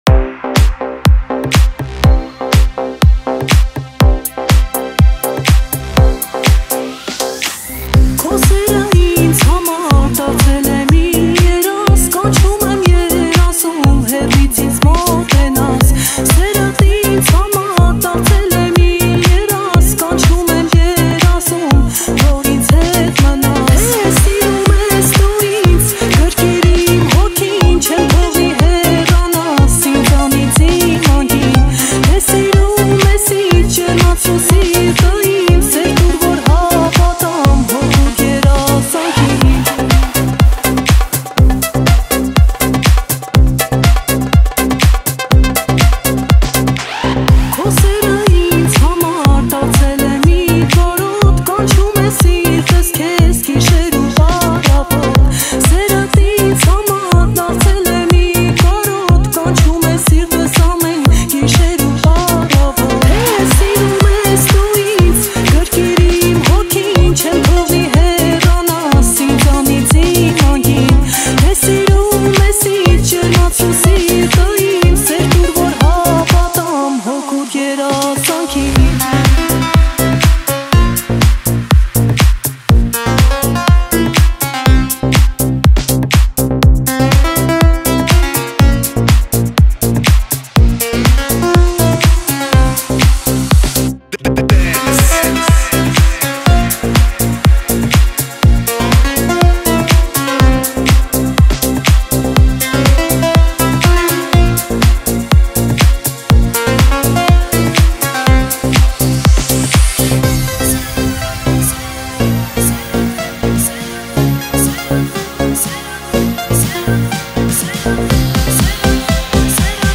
_House_Dance_Club__.mp3